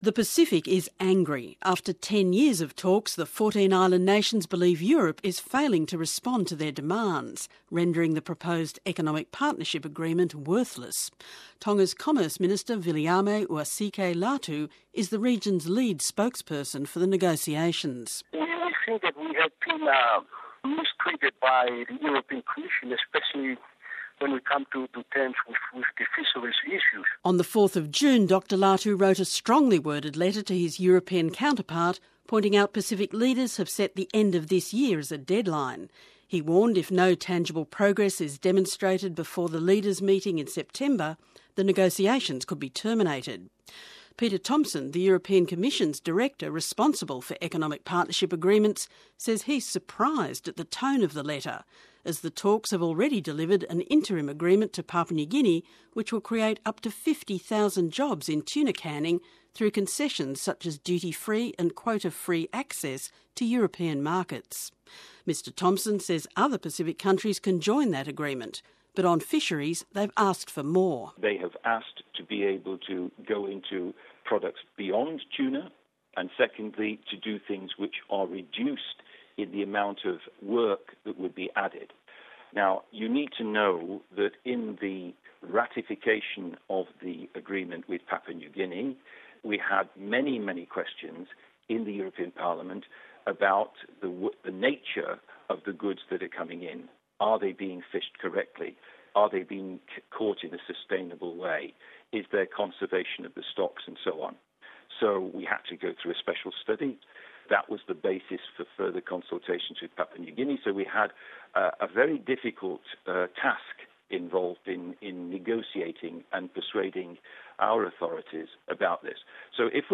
Speakers: Dr Viliami Uasike Latu, Pacific lead spokesperson on Economic Partnership negotiations and Tongan Commerce Minister